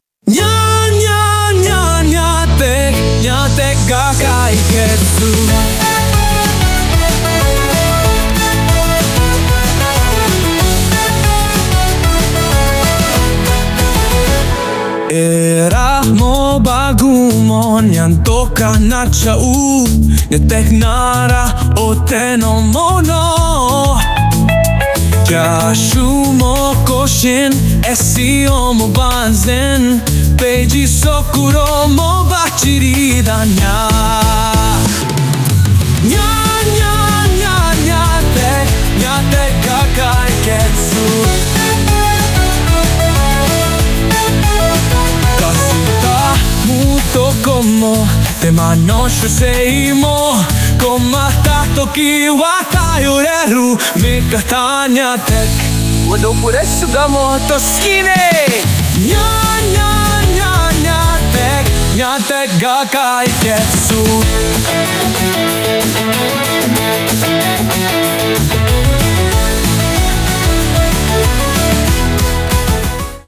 その為、今回は、Suno AIで生成された曲を、Adobe Audtionを使ってマスタリングしてみました。
マスタリング後の音源
音の厚みが違うような気がするにゃ